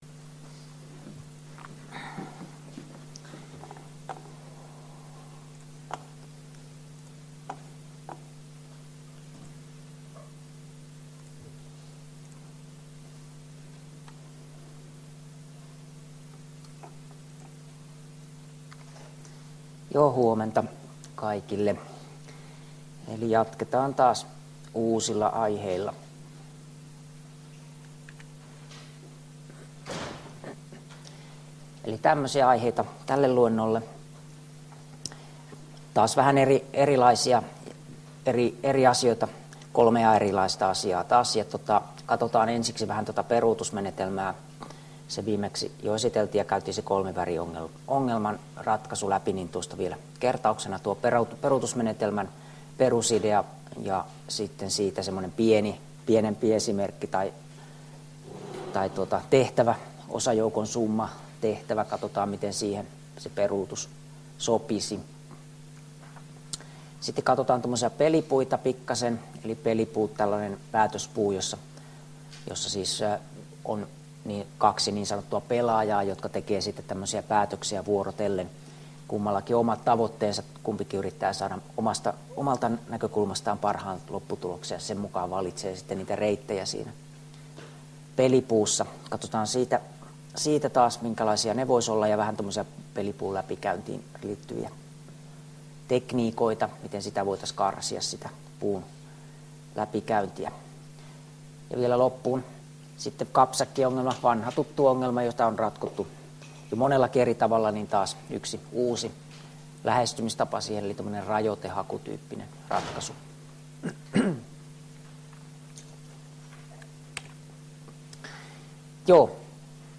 Luento 10 — Moniviestin